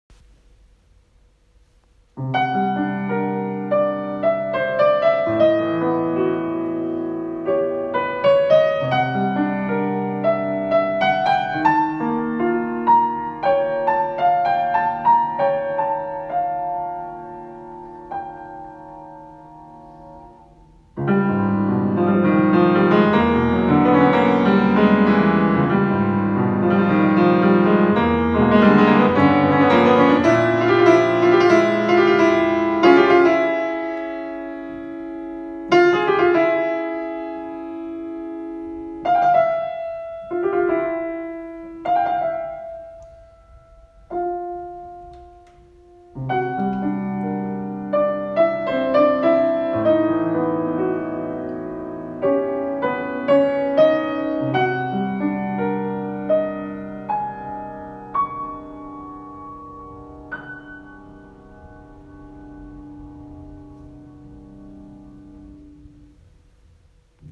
Esta pieza para piano
Instrumentación: piano solo